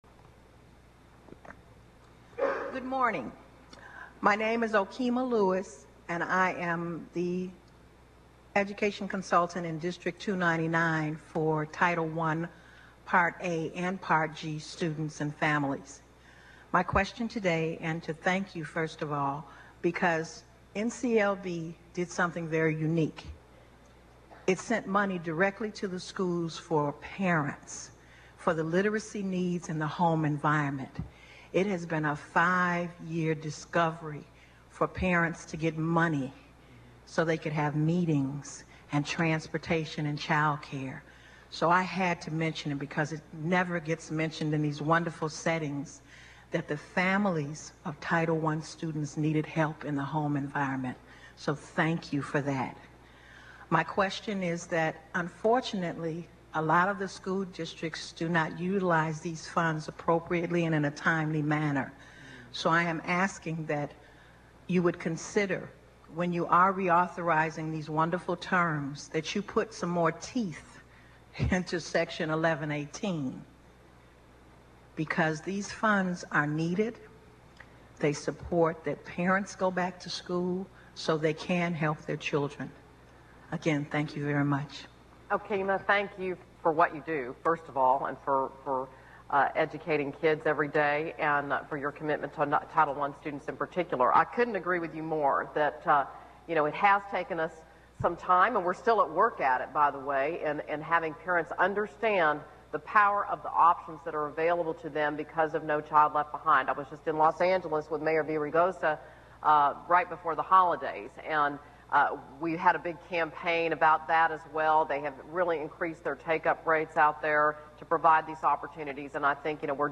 Yesterday, January 8th, the U.S. Chamber of Commerce had a gathering to commemorate the 5th anniversary of the No Child Left Behind law.  Margaret Spellings was the featured speaker.  During the Question and Answer period, there were two questions asked of Spellings that I think will interest you.